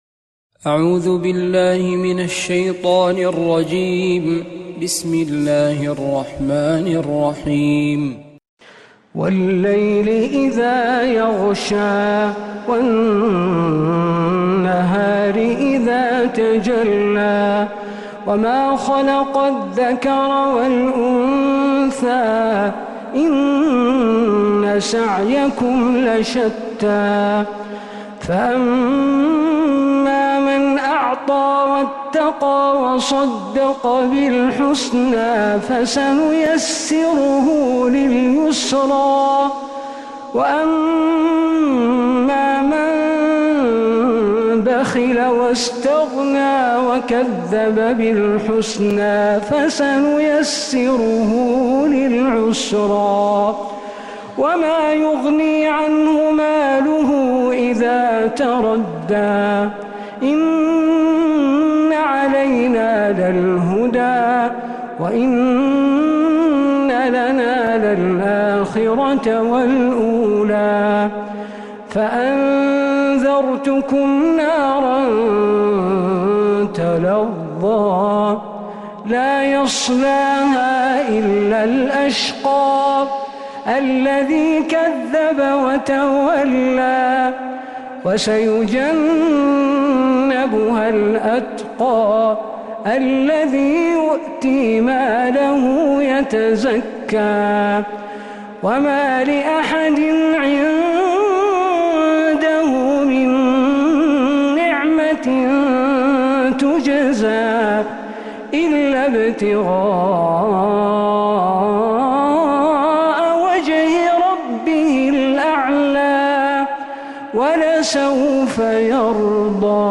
سورة الليل كاملة من عشائيات الحرم النبوي